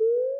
Fx (Siren).wav